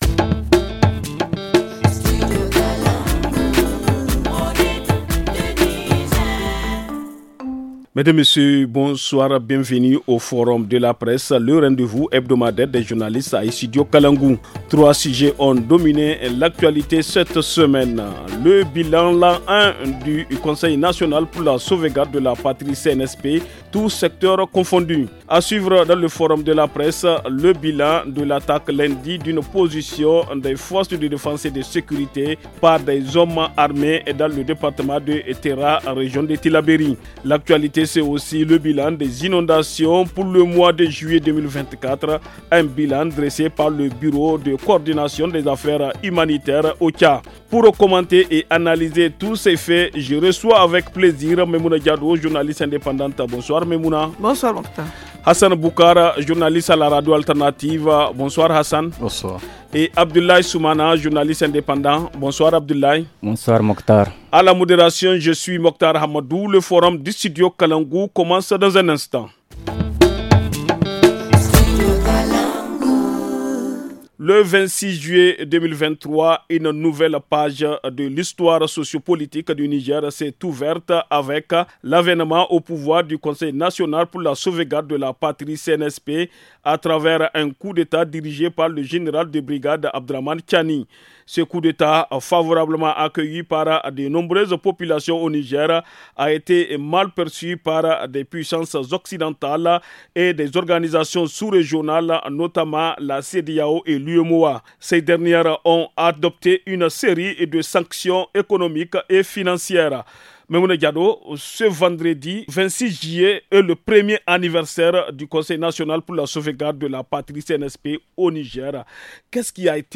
journaliste indépendante
journaliste indépendant.